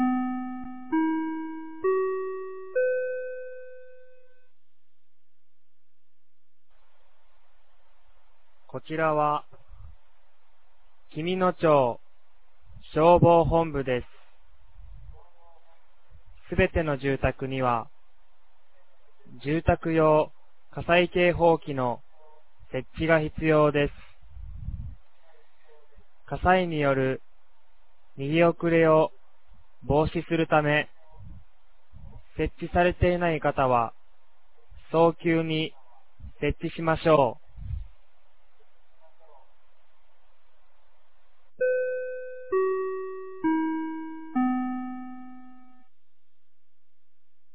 2023年08月05日 16時00分に、紀美野町より全地区へ放送がありました。